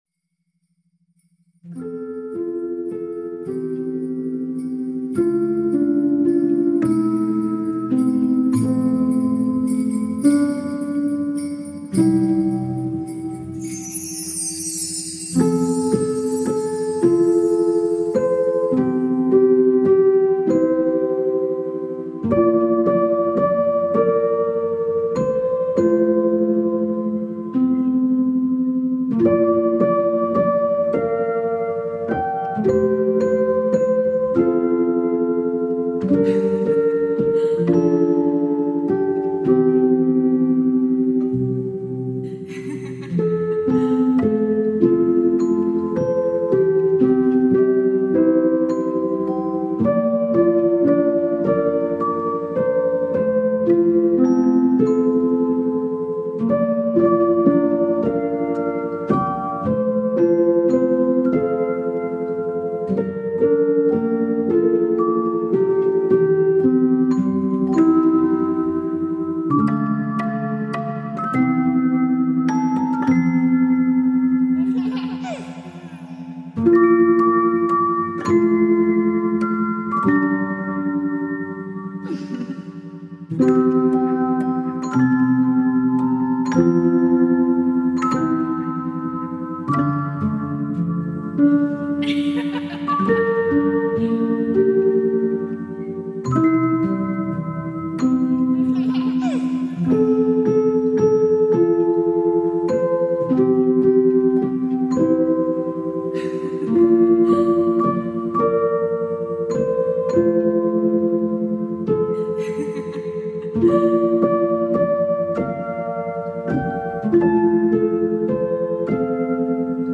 sunetelor terapeutice